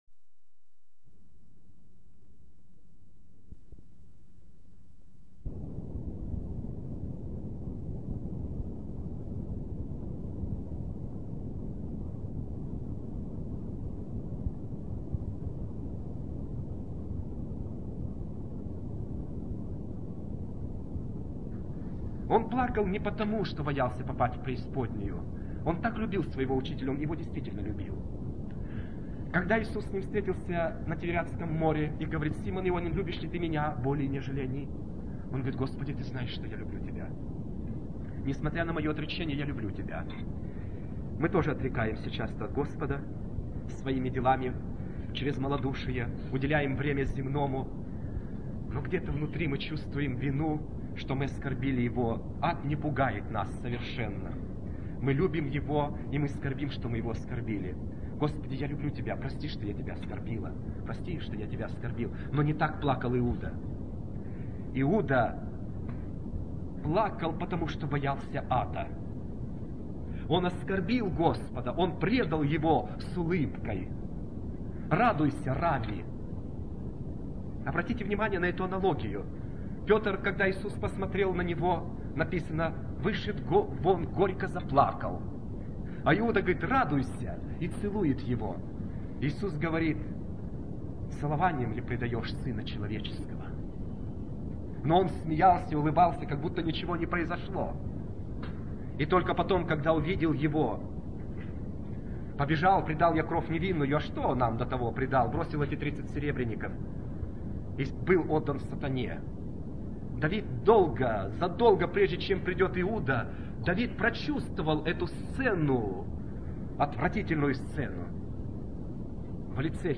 Всего 37 Проповедей Скачать Все Проповеди ~ 1.6 GB